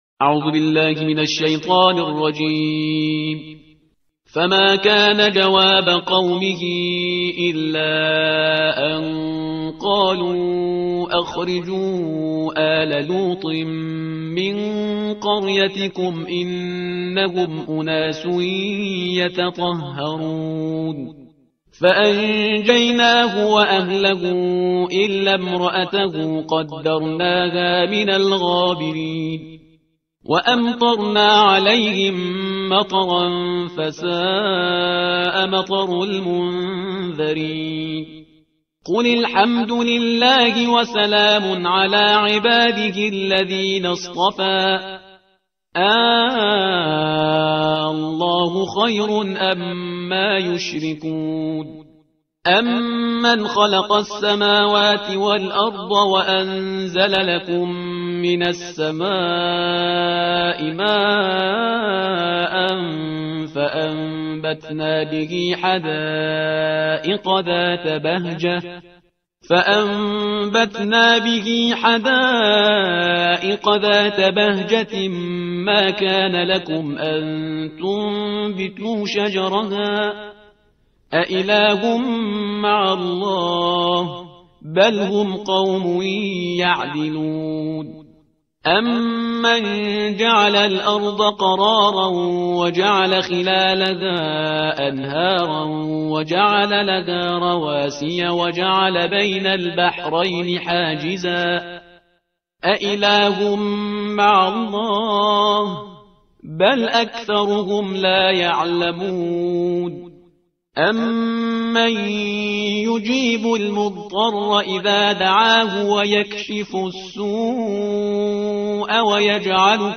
ترتیل صفحه 382 قرآن با صدای شهریار پرهیزگار